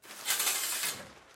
家庭 " RB H 家庭衣柜 01
描述：木制滑动壁橱门打开关闭。立体声录音，用Zaxcom Deva II录音，森海塞尔MKH 30和MKH 40作为中置，解码为AB立体声。
标签： 窗帘 家居 室内 淋浴
声道立体声